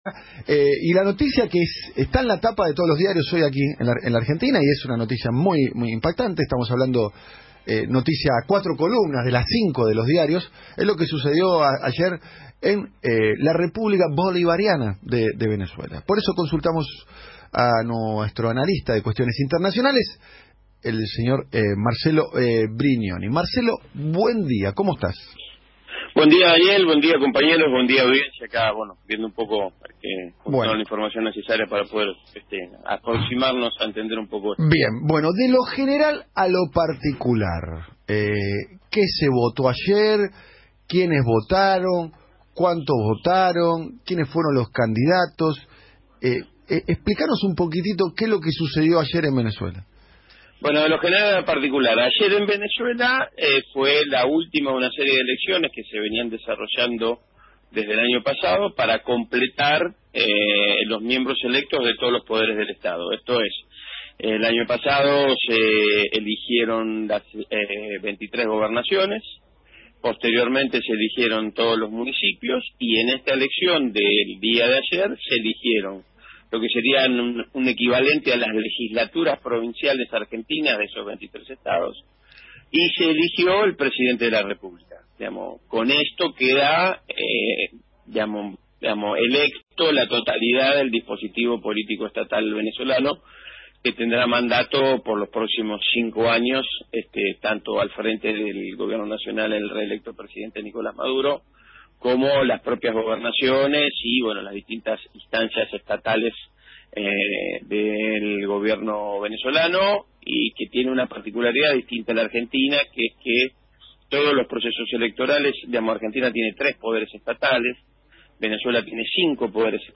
Análisis de las Elecciones Presidenciales de Venezuela, en el Programa Siempre es Hoy, de Radio del Plata de Argentina, conducido por Daniel Tognetti